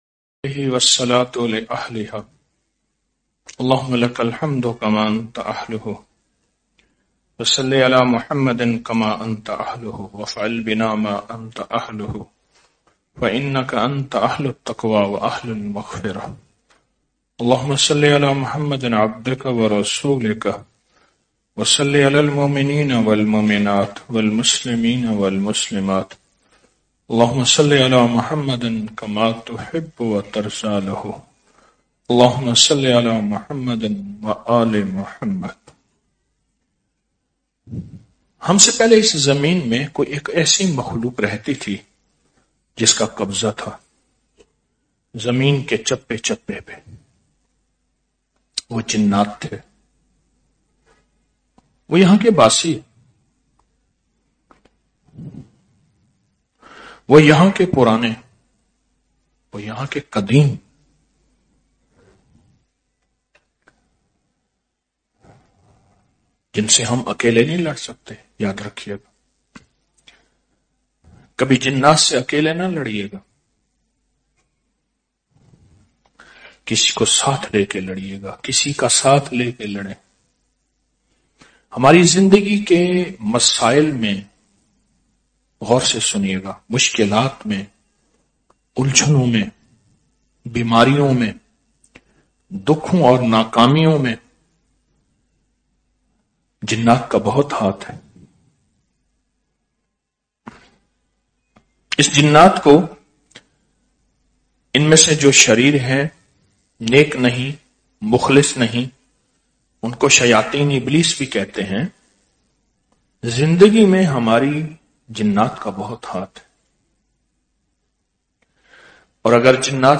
Audio Speech -